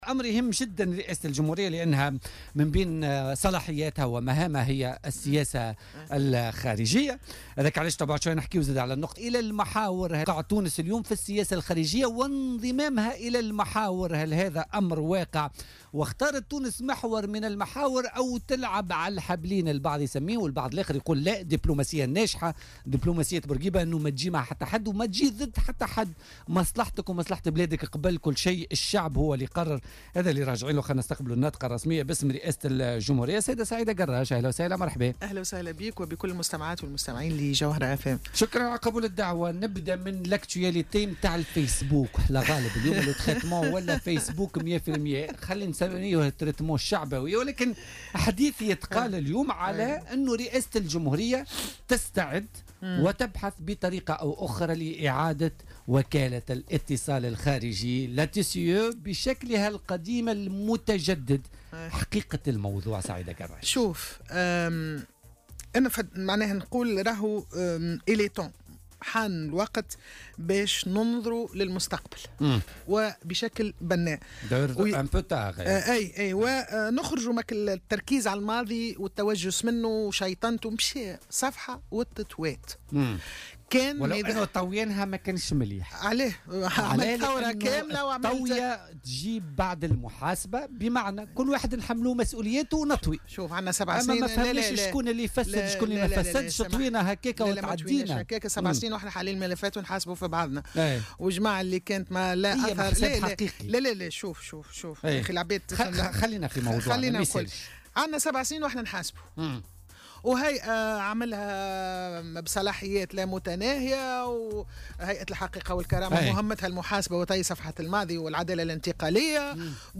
وشدّدت ضيفة "بوليتيكا" على "الجوهرة اف أم" على ضرورة قيام مؤسسة تعنى بالترويج لصورة تونس في الخارج خدمة للبلاد وليس لاشخاص أو لنظام بعينه كما كانت عليه وكالة الاتصال الخارجي، قبل الثورة حيث تم استغلال هذه المؤسسة العمومية لخدمة صورة الرئيس الأسبق زين العابدين بن علي.